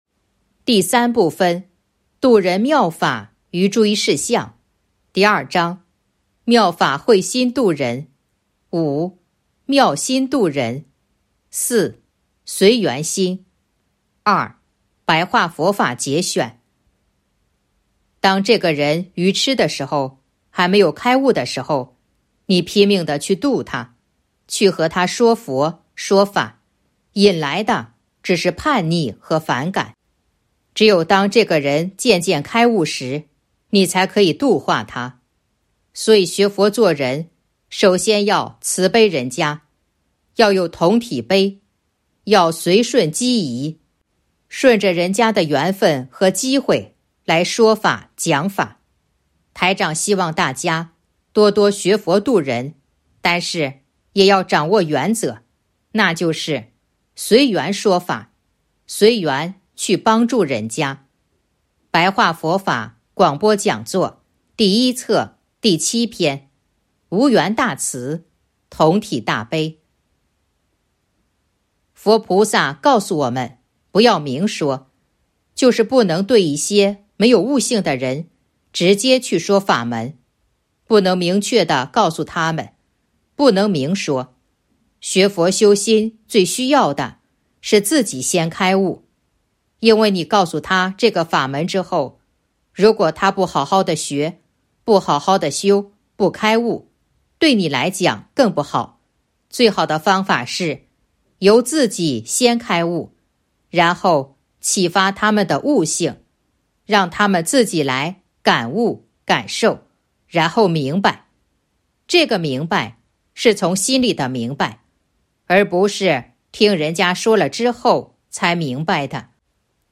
白话佛法节选《弘法度人手册》【有声书】